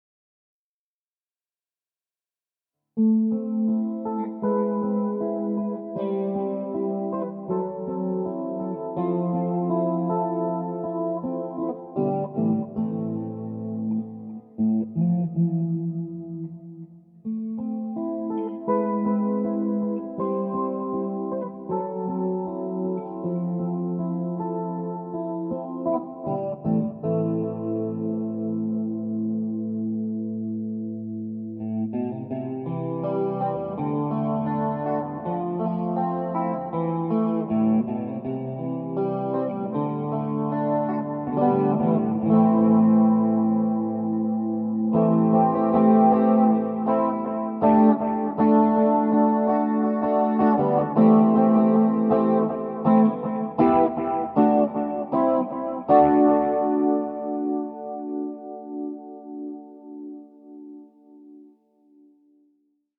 Ich hab auch einige Aufnahmen mit veränderter Tonblende gemacht.